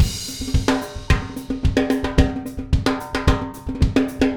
Звуки латино
Latin Drumbeat 14 Groove